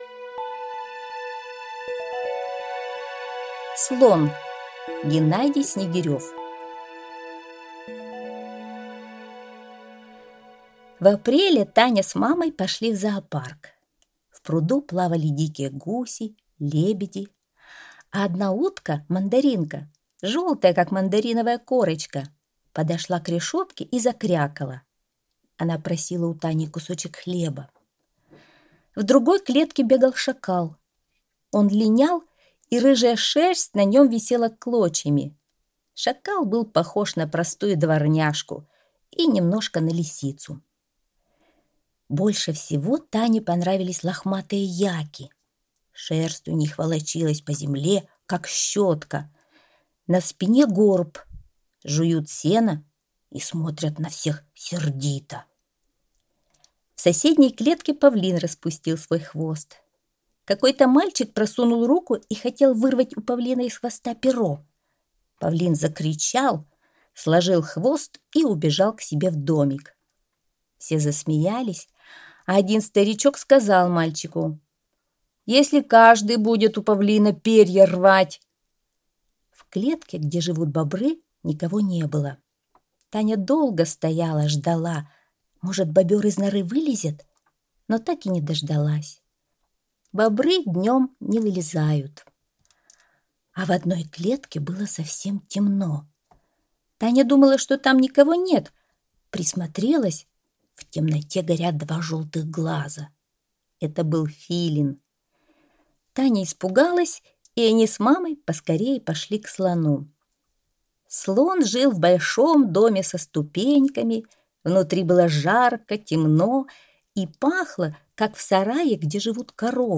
Аудиорассказ «Слон»